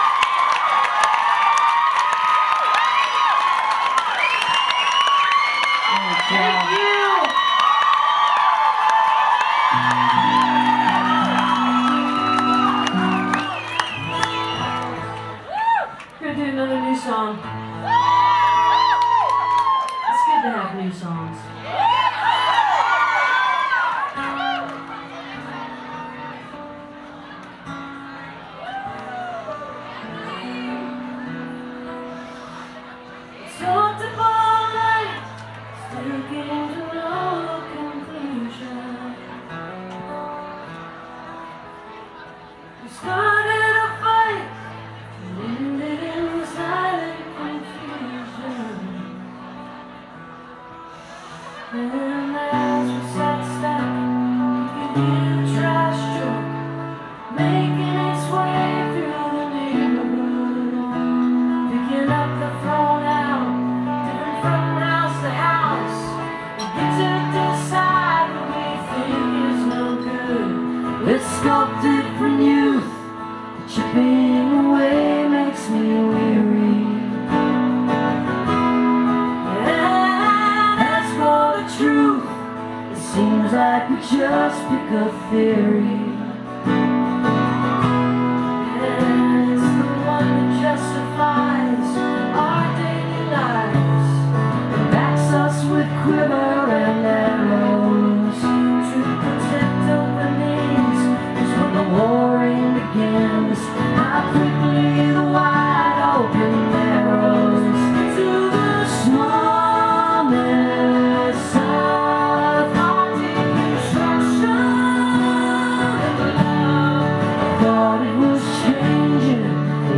(acoustic duo)